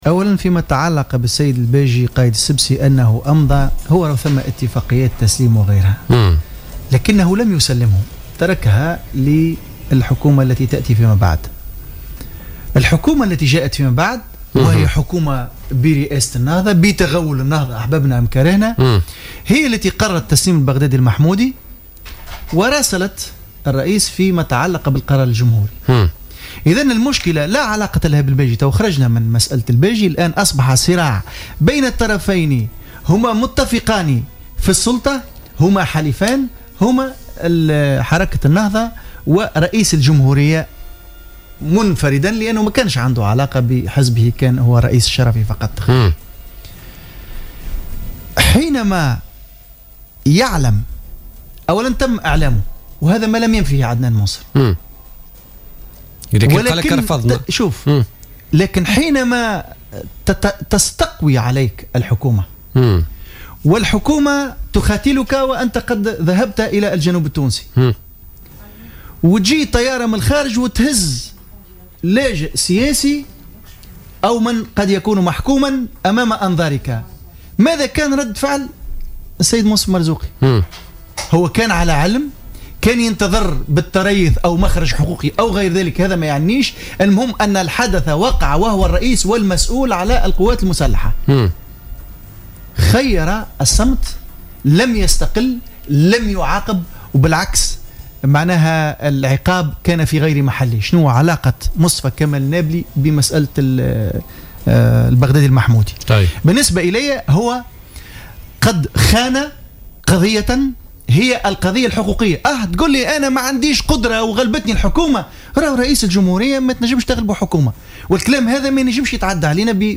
أكد التوهامي العبدولي رئيس حزب الحركة الوطنية ضيف برنامج "بوليتيكا" اليوم الثلاثاء أن رئيس الجمهورية المؤقت المنصف المرزوقي كان على علم بتسليم رئيس الوزراء الليبي البغدادي المحمودي.